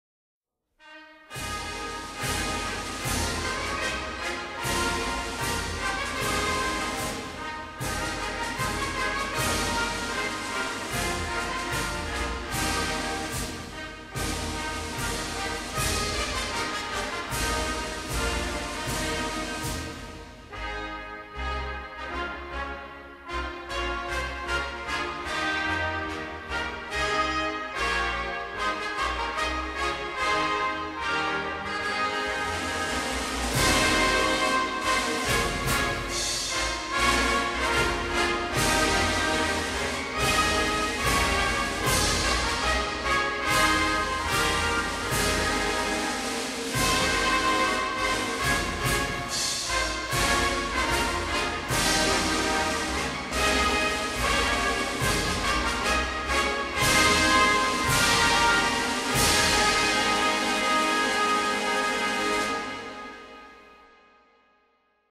National Anthem of Arab Darussalam